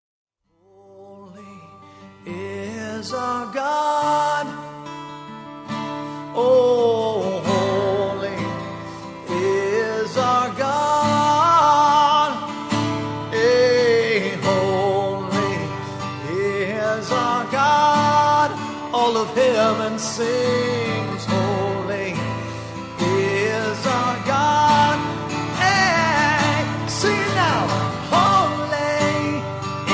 Worship Music